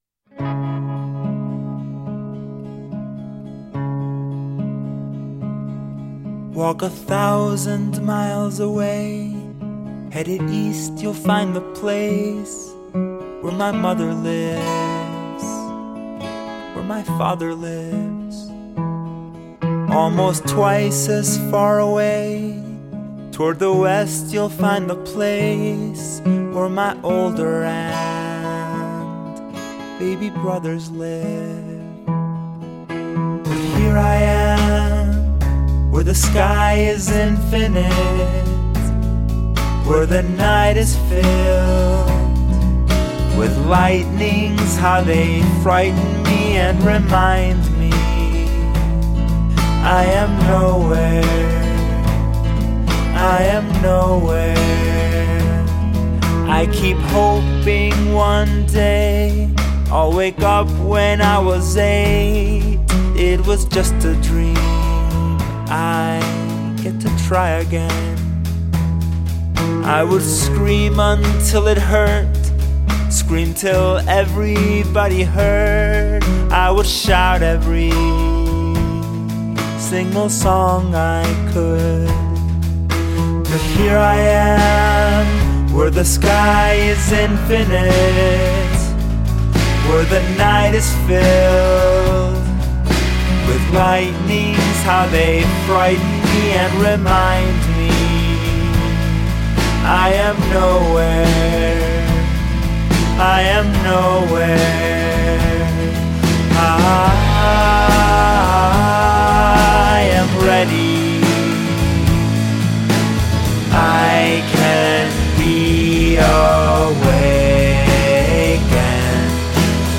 LA indie folk artist